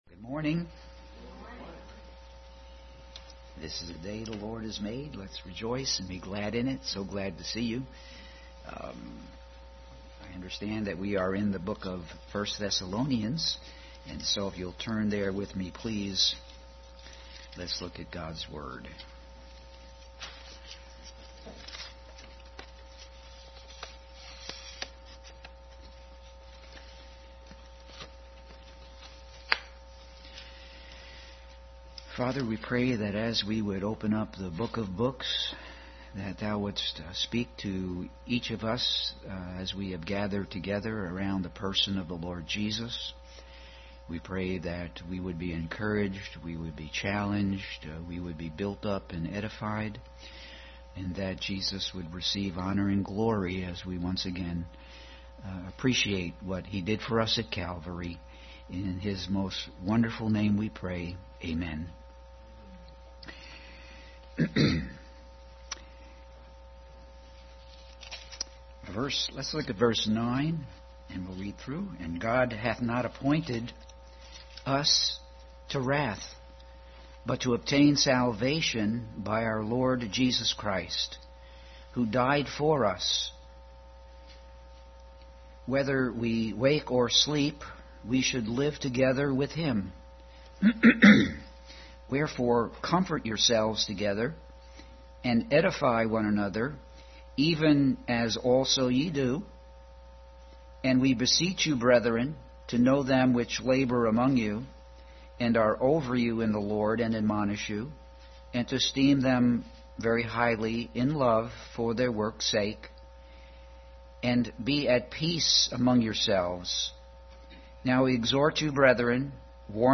Bible Text: 1Thessalonians 5:9-28, Isaiah 8:20, Proverbs 20:22, Psalm 34:14 | Adult Sunday School. Continued study in Thessalonians.